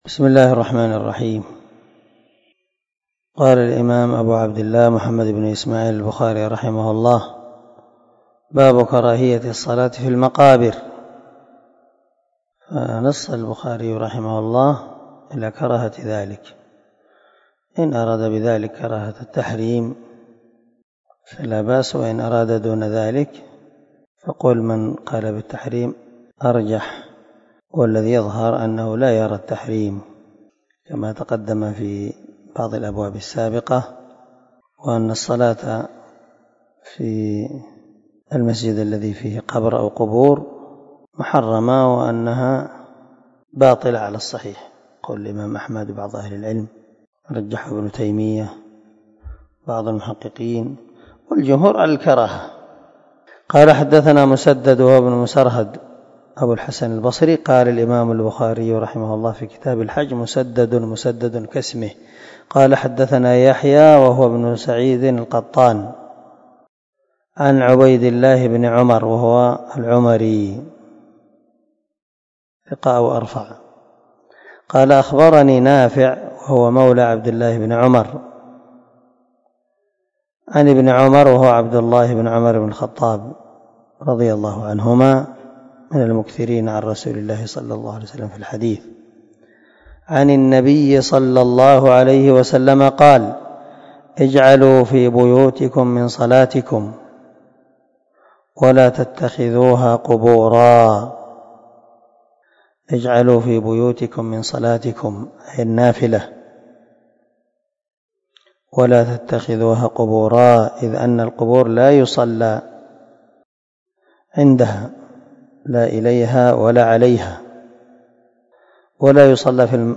323الدرس 56 من شرح كتاب الصلاة حديث رقم ( 432 ) من صحيح البخاري
دار الحديث- المَحاوِلة- الصبيحة.